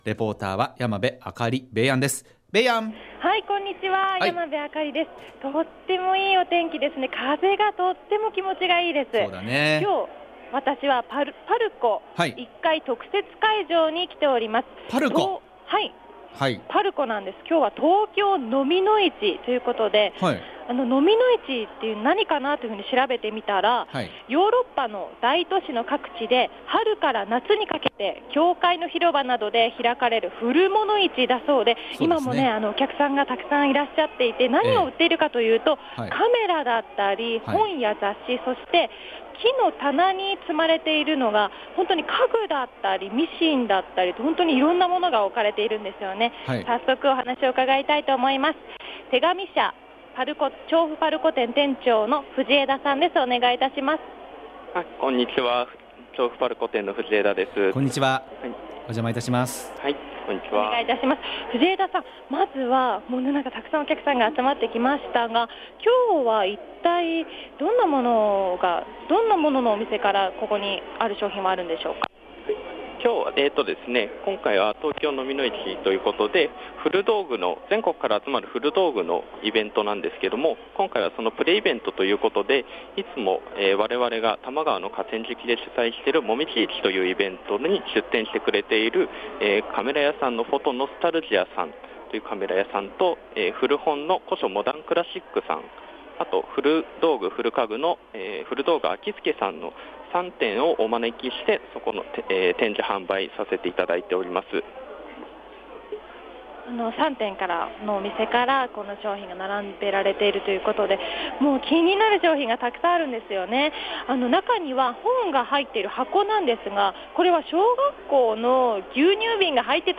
びゅーサン 街角レポート
今日は調布パルコ１階特設会場で開かれていた、東京蚤の市プレイベントへお邪魔しました。 蚤の市とは、ヨーロッパの大都市の各地で春から夏にかけて教会の広場などで開かれる古物市だそうです！
美術の先生が、油絵の具がべったりついたパレットを購入されていたそうです＾＾ 今日はカメラを購入されていたお客さんにもお話をうかがいました＾＾ 真っ白い棚にずらっと並べられたカメラは、１番古いもので１９３６年製のもの。